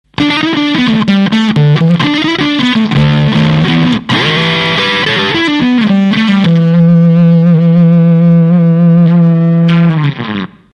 「FUZZ EXPLOSION ON１(80kbMP3)」
です。おそらく「FUZZ１００％」な音の筈です。